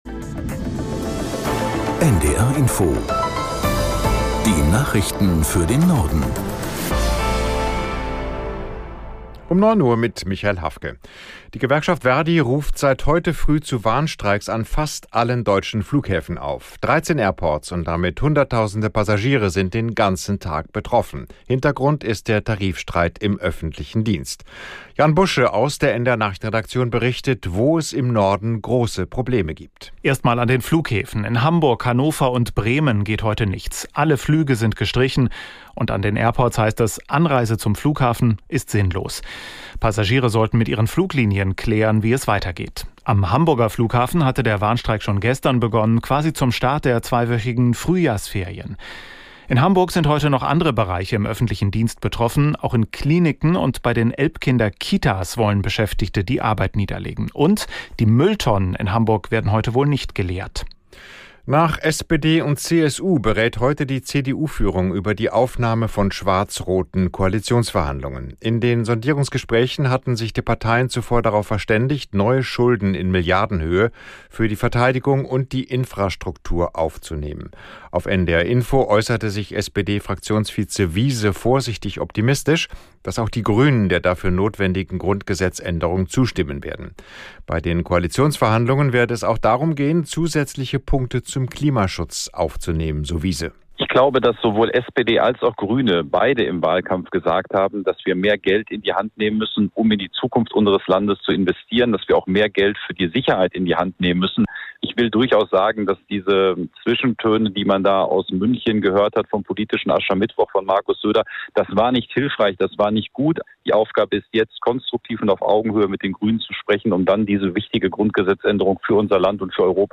Nachrichten für den Norden.